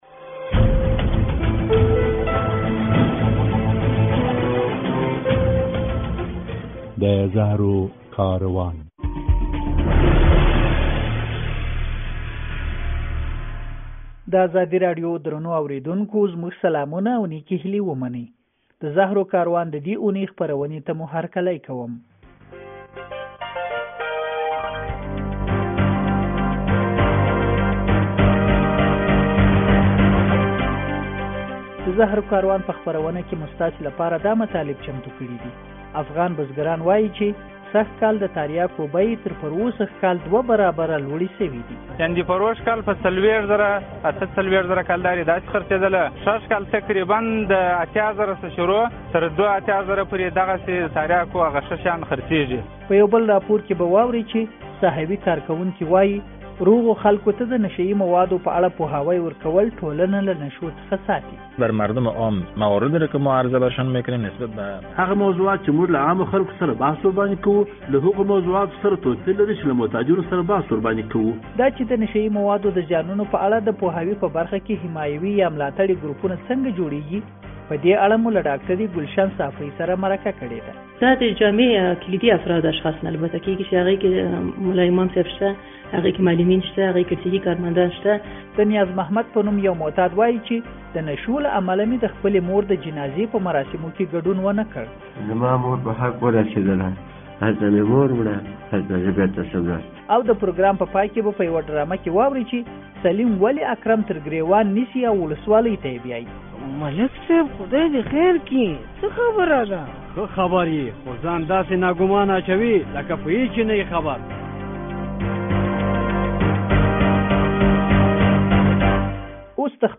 • په پای کې ډرامه هم لرو.